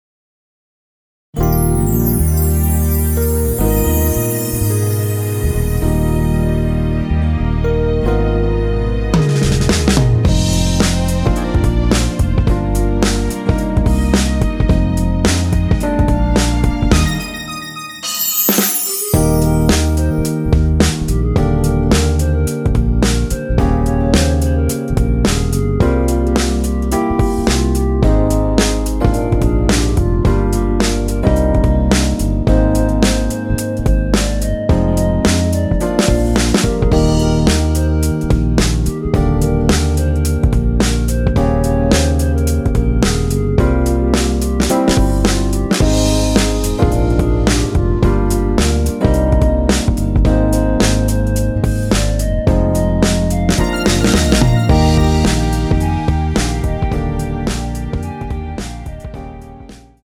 원키에서(-2)내린 멜로디 포함된 MR입니다.(미리듣기 확인)
Eb
앞부분30초, 뒷부분30초씩 편집해서 올려 드리고 있습니다.
중간에 음이 끈어지고 다시 나오는 이유는